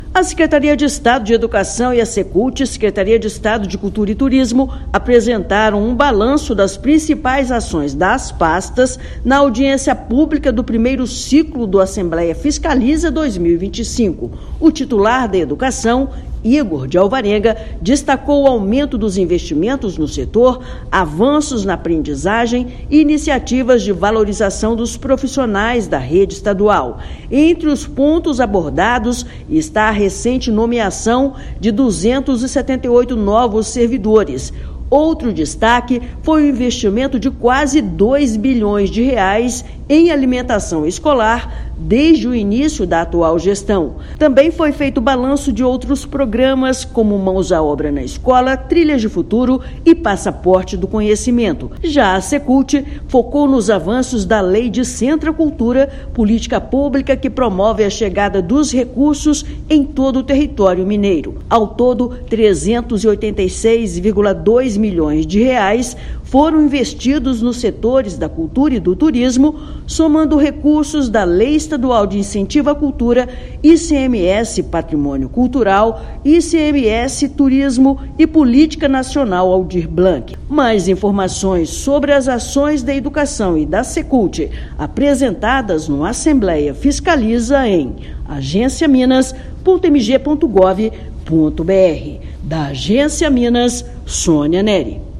Entre os pontos abordados estão aumento do aporte financeiro na merenda escolar, infraestrutura, valorização dos servidores, além impactos das Lei Descentra Cultura. Ouça matéria de rádio.